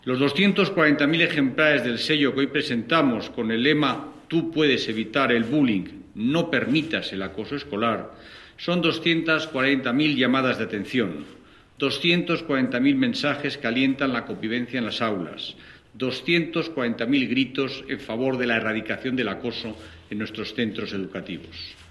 Declaraciones de Íñigo Méndez de Vigo Audio